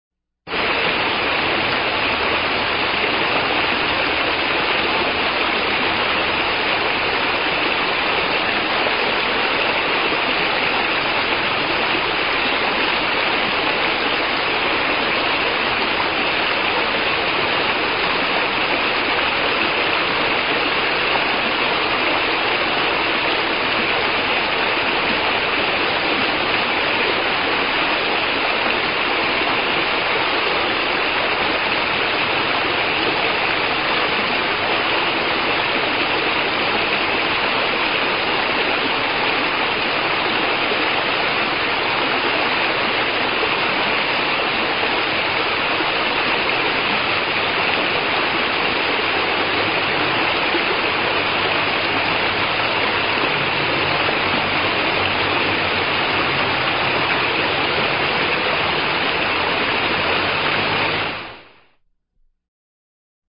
Pianeta Gratis - Audio - -Natura - Acquatici
acqua_water06.wav